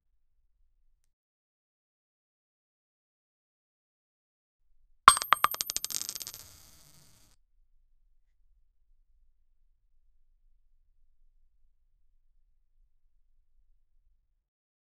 Body-movement Sound Effects - Free AI Generator & Downloads
drop-to-its-knees-4jfqu753.wav